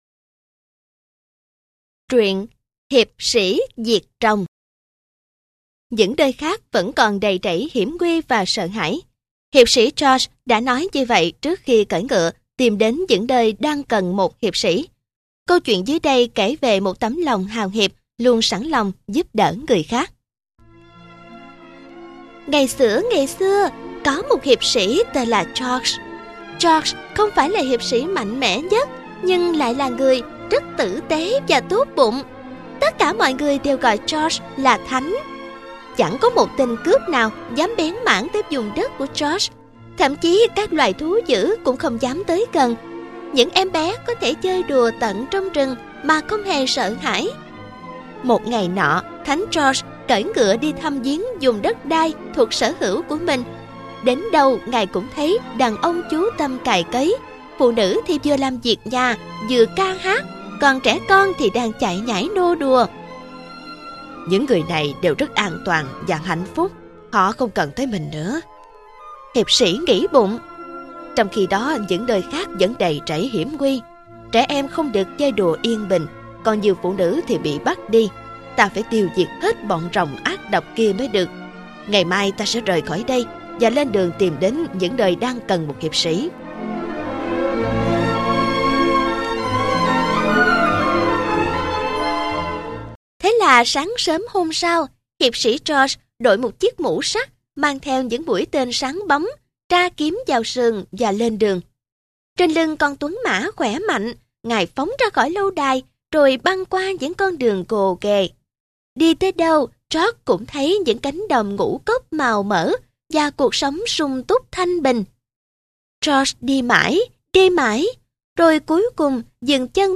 * Thể loại: Sách nói